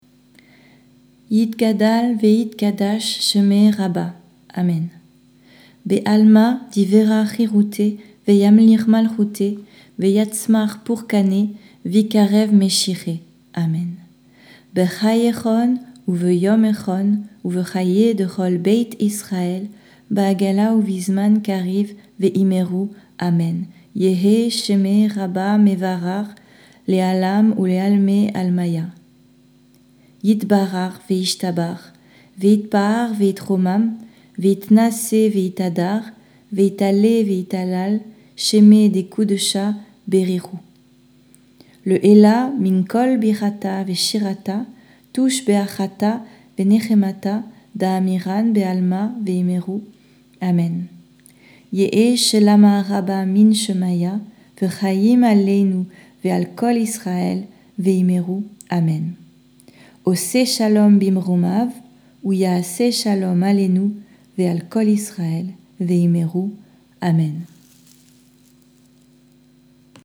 Kaddish-des-endeuilles-parle.mp3